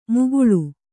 ♪ muguḷu